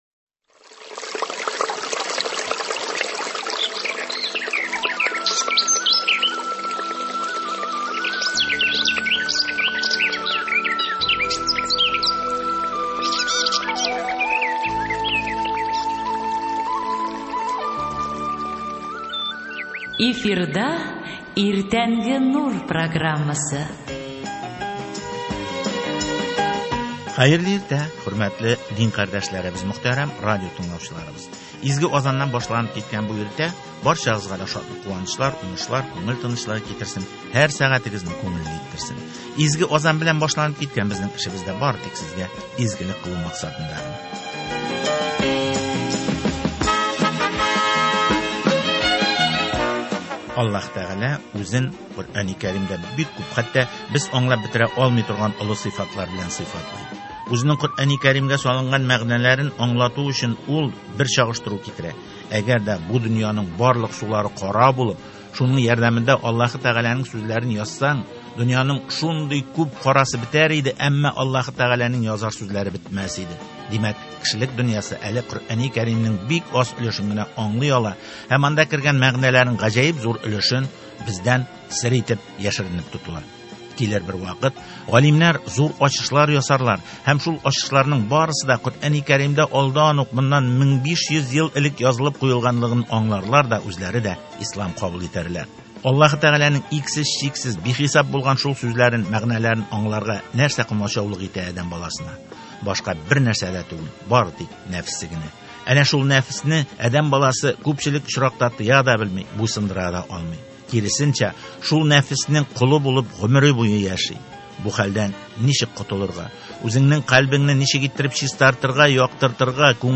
сүрәнең Адәм баласын тәрбияләүдәге роле, андагы хикмәтләрнең үзенчәлекләре хакында әңгәмә.